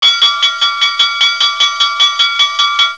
dings.wav